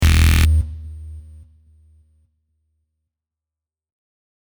Error 4.mp3